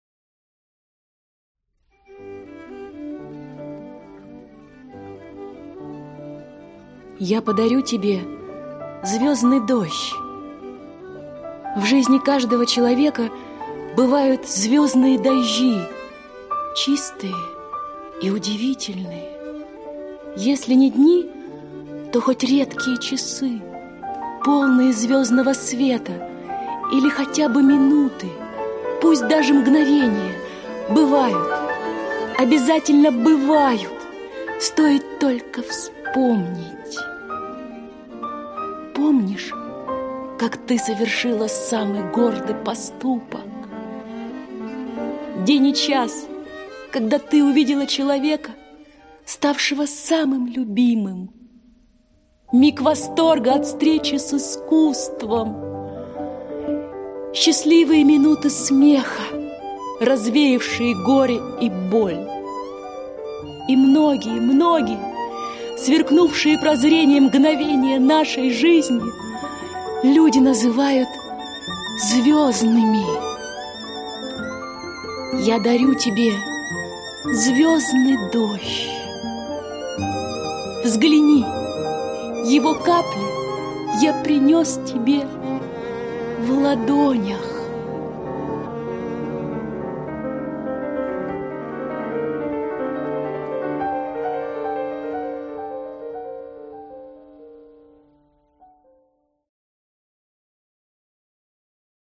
Аудиокнига Одиночество в толпе | Библиотека аудиокниг